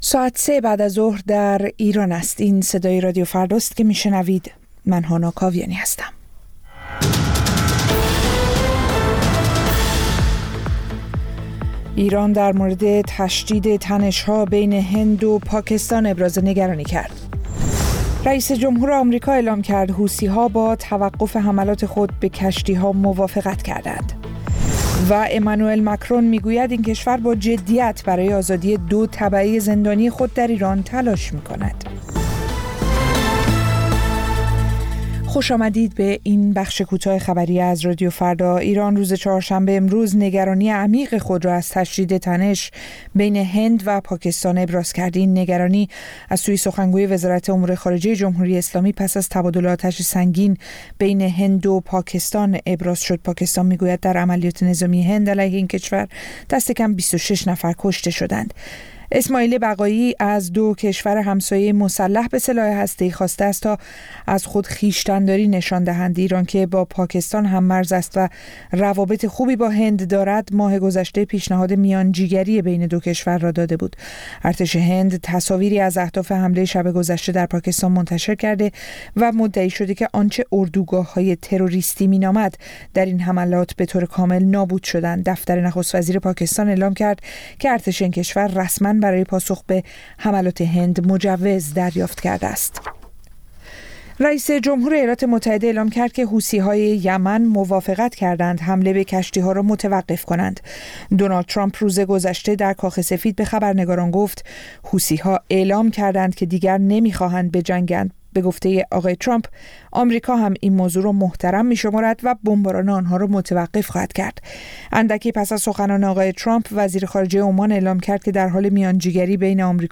سرخط خبرها ۱۵:۰۰